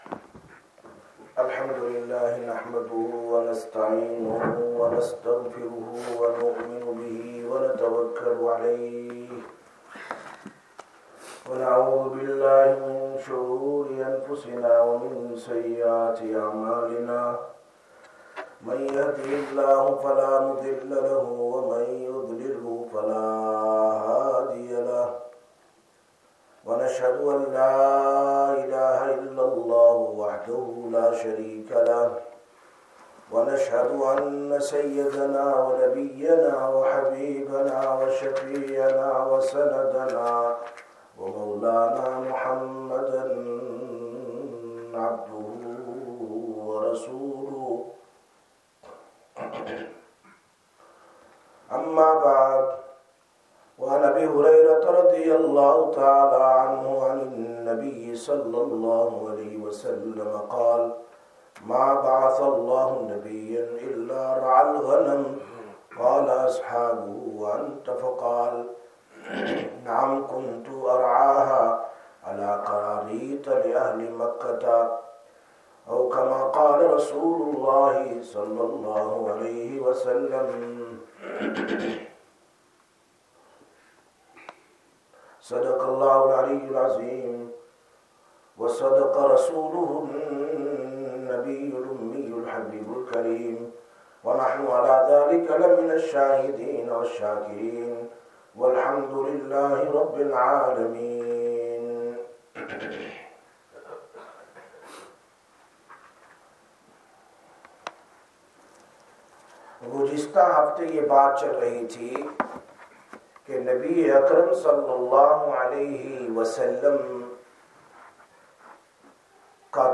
19/01/2022 Sisters Bayan, Masjid Quba